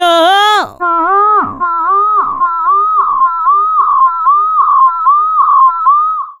ECHODISTO.wav